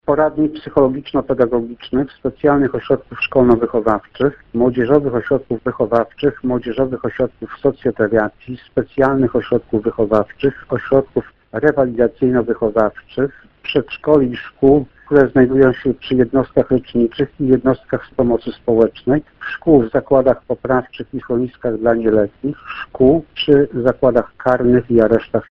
Listę obiektów, które specustawa w sprawie koronawirusa traktuje jako wyjątki, przypomina Wojciech Cybulski, warmińsko-mazurski wicekurator oświaty.